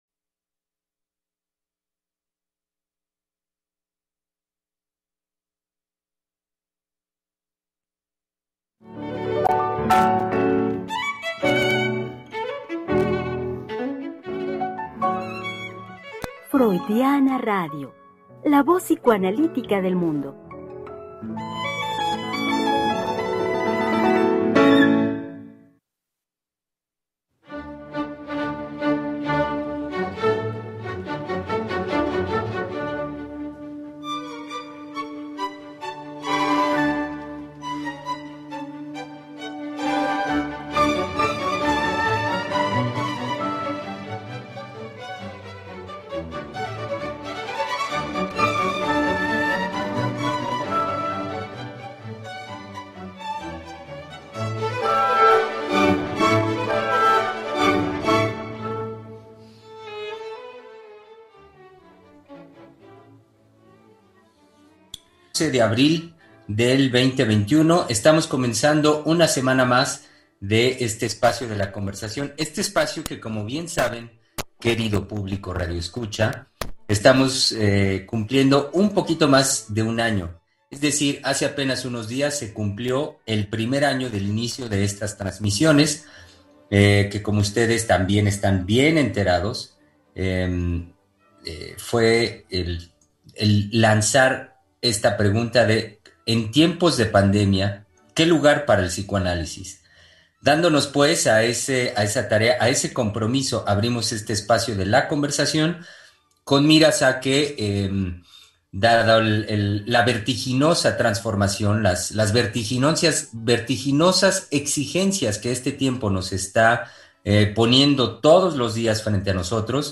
Tres Mujeres Psicoanalistas Hablando de la Vida Cotidiana 1a parte. – Freudiana radio
Programa transmitido el 12 de abril del 2021.
Tres-Mujeres-Psicoanalistas-Hablando-de-la-Vida-Cotidiana-1.mp3